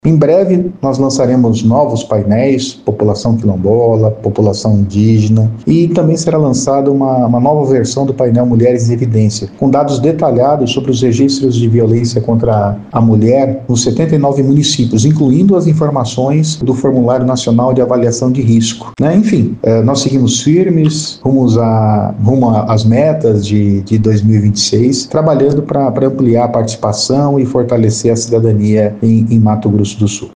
Em entrevista ao programa “Agora 104” o coordenador do projeto